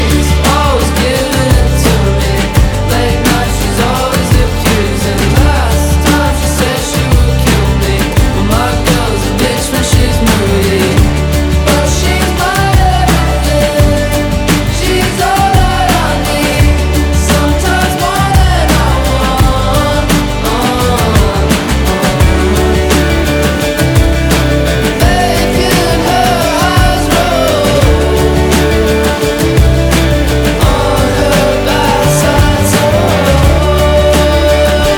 2025-05-09 Жанр: Альтернатива Длительность